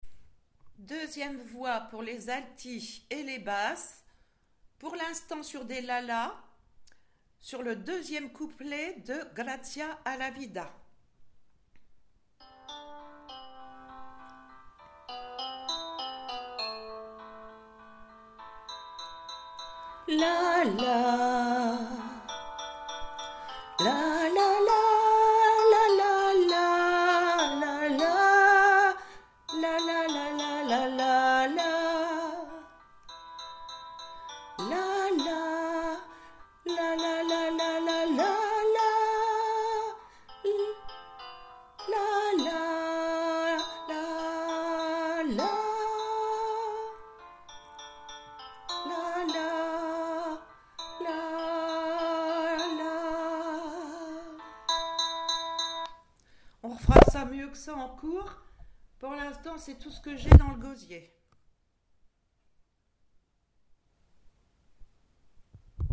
Gracias Alti Basses